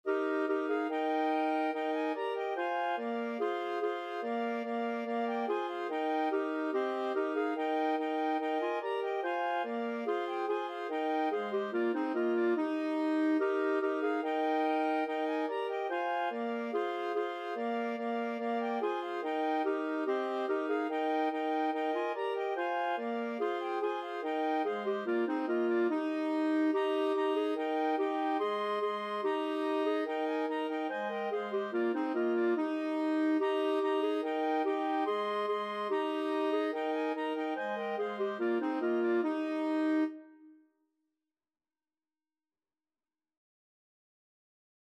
Free Sheet music for Clarinet Quartet
Clarinet 1Clarinet 2Clarinet 3Clarinet 4
Eb major (Sounding Pitch) F major (Clarinet in Bb) (View more Eb major Music for Clarinet Quartet )
4/4 (View more 4/4 Music)
Classical (View more Classical Clarinet Quartet Music)
danserye_18_ronde_4CL.mp3